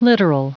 Prononciation du mot literal en anglais (fichier audio)
Prononciation du mot : literal